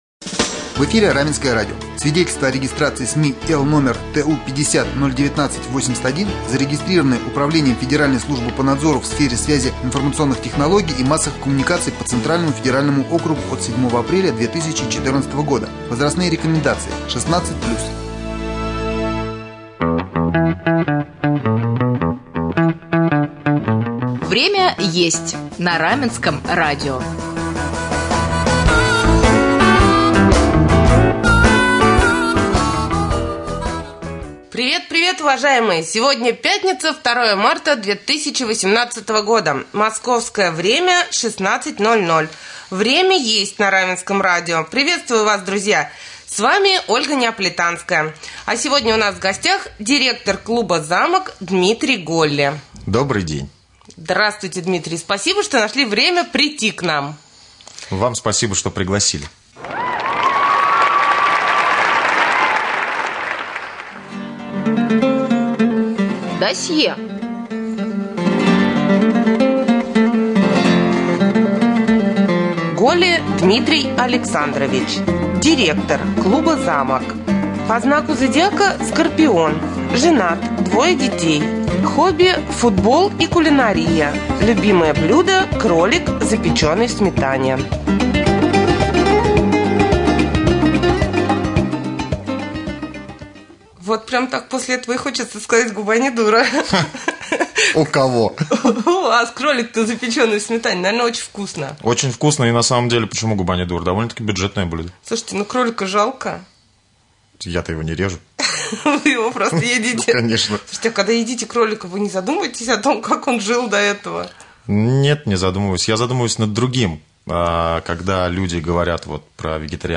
в прямом эфире передачи «Время Есть» на Раменском радио